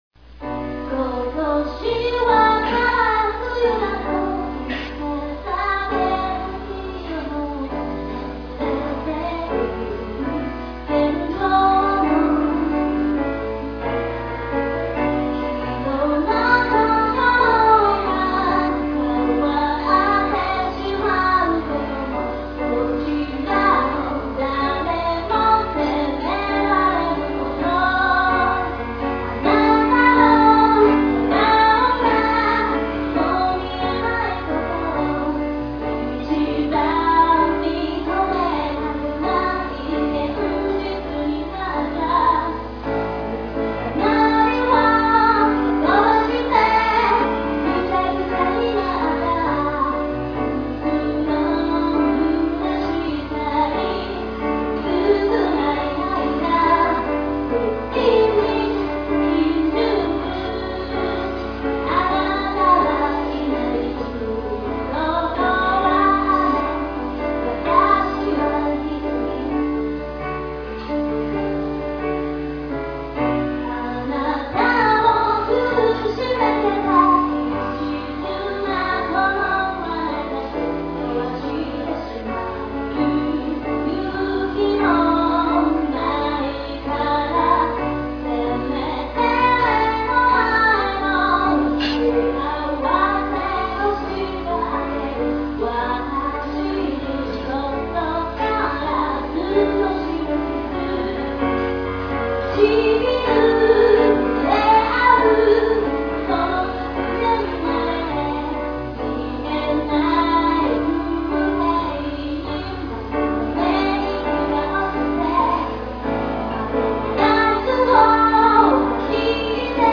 打ち込みをひたすら馬鹿にされまくった曲です。